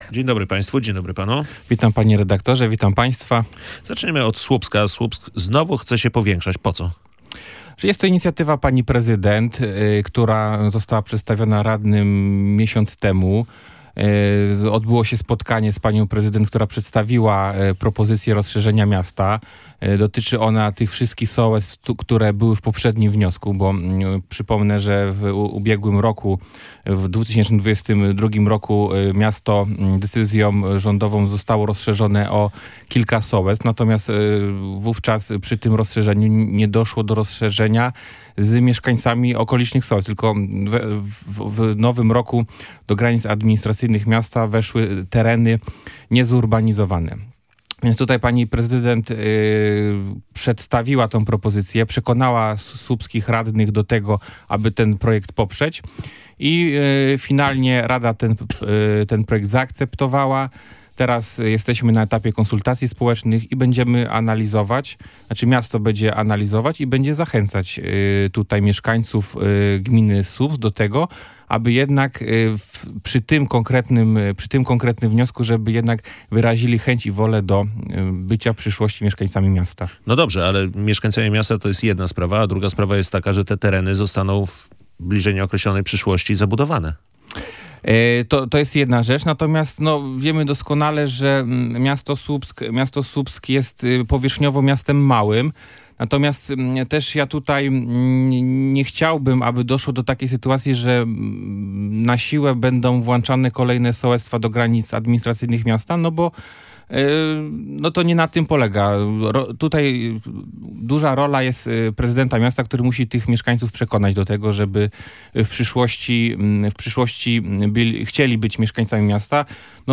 Zapytajmy mieszkańców sołectw o to, czy faktycznie chcą leżeć w administracyjnych granicach Słupska – mówił w Radiu Gdańsk Jacek Szaran, słupski radny Prawa i Sprawiedliwości.